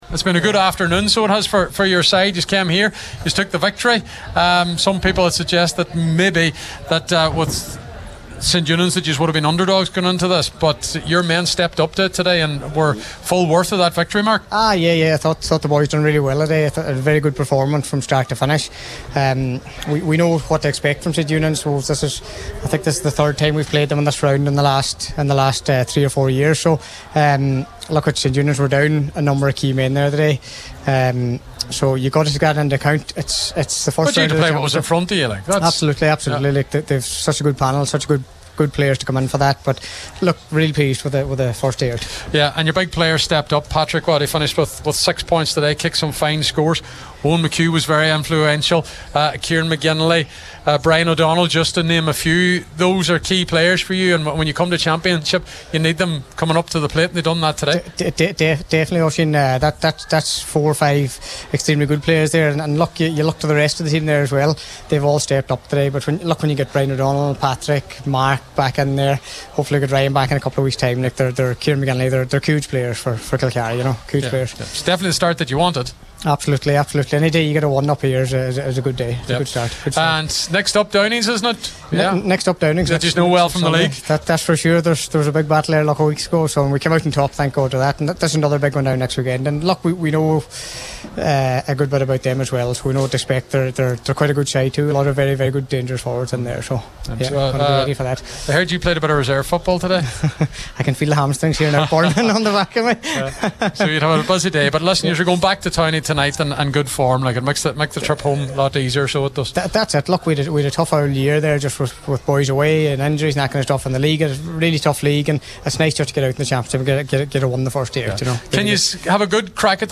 after the game…